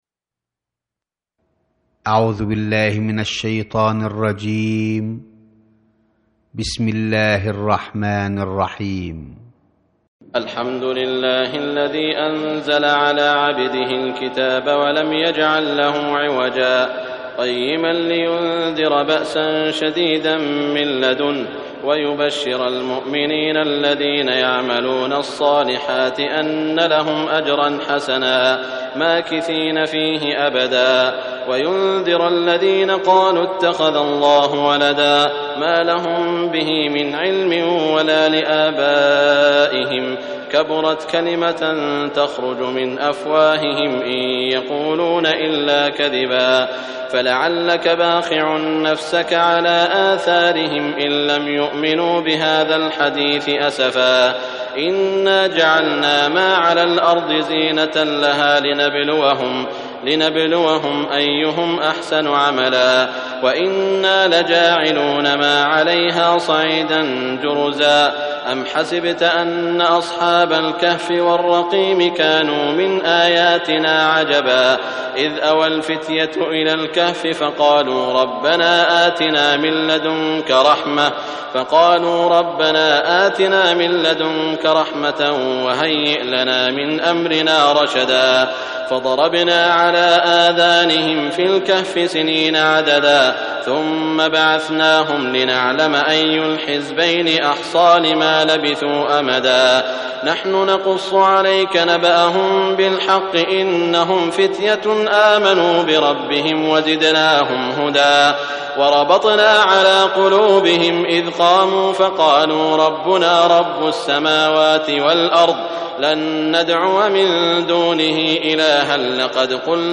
r�citation mp3 - Hafidhun Sudais wa Shuraym (qdlfm) - 13 317 ko ;
018-Surat_Al_Kahf_(La_caverne)_Cheikh_Sudais_Wa_Shuraym.mp3